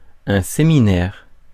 Ääntäminen
Synonyymit entretien Ääntäminen France: IPA: [se.mi.nɛʁ] Haettu sana löytyi näillä lähdekielillä: ranska Käännöksiä ei löytynyt valitulle kohdekielelle.